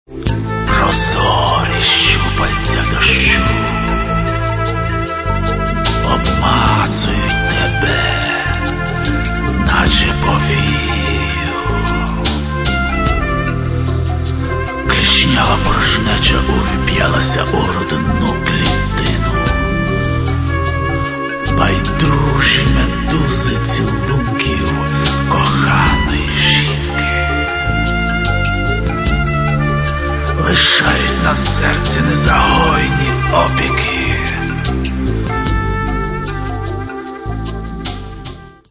Каталог -> Рок та альтернатива -> Готика